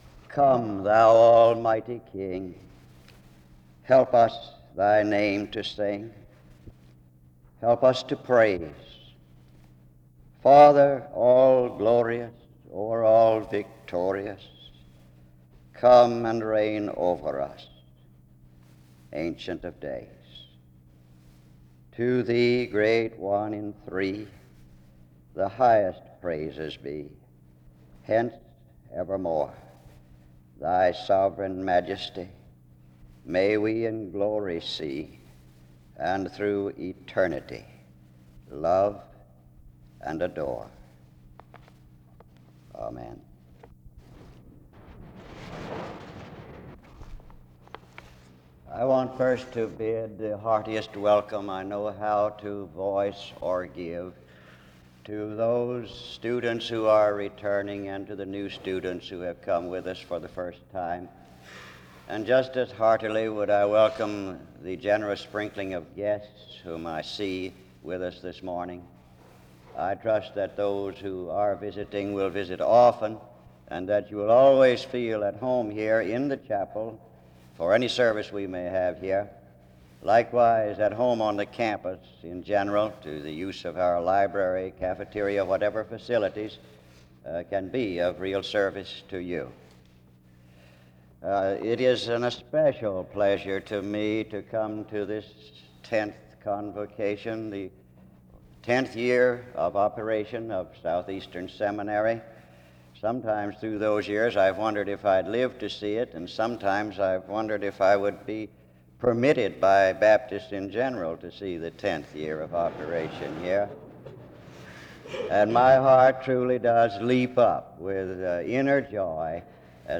Download .mp3 Description The first and fourth stanzas of the hymn “Come, Thou Almighty King” is prayed over the congregation at the tenth convocation of the seminary’s existence (00:00-00:42).